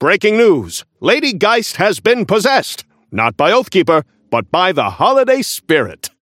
Newscaster voice line - Breaking news: Lady Geist has been possessed!
Newscaster_seasonal_ghost_unlock_01_alt_01.mp3